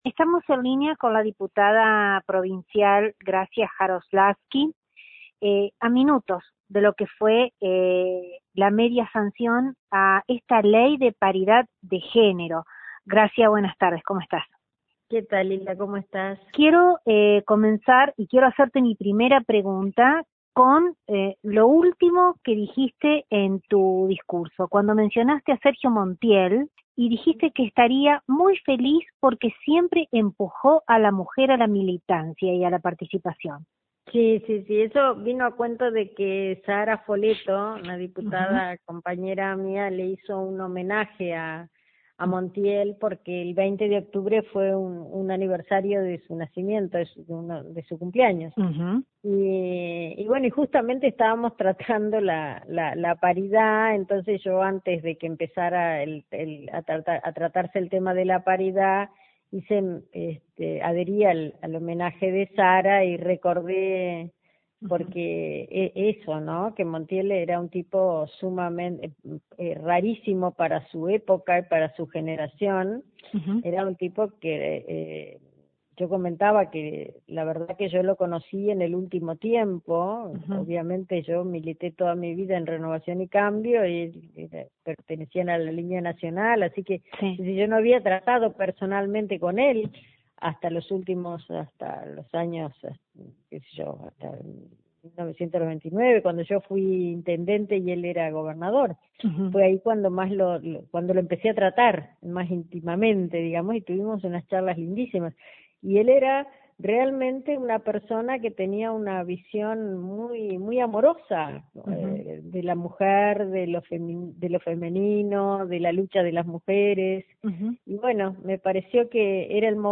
Finalizada la jornada, desde LT39 NOTICIAS, dialogamos con la legisladora; quien prima facie hizo un paneo, de la estructura que definió su alocución y la significancia de esta ley, la que claramente, con esta media sanción, está a punto de derribar construcciones culturales, donde la mujer quedaba en segundo plano.